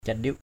/ca-ɗiʊʔ/ (cv.) kandip kQ{P 1.